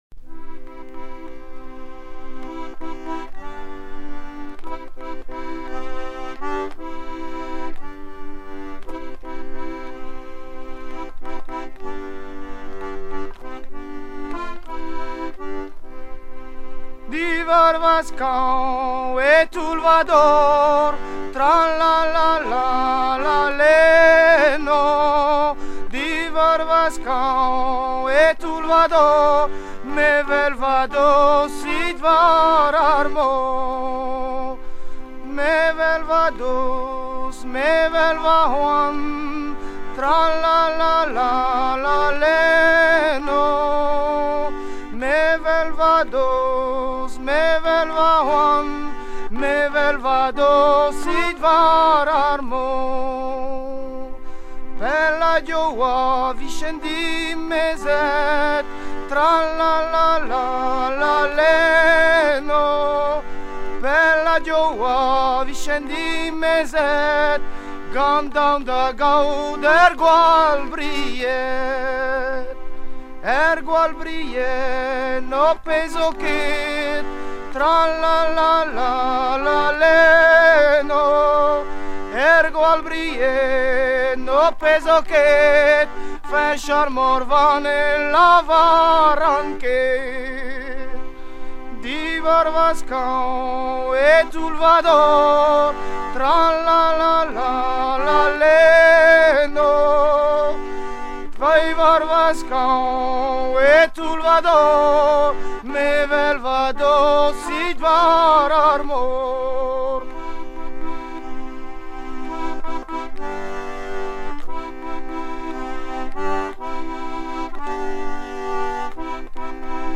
Version recueillie dans les années 1970
Genre strophique
Chants de marins traditionnels